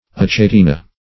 Search Result for " achatina" : The Collaborative International Dictionary of English v.0.48: Achatina \Ach`a*ti"na\, n. [NL., from Gr.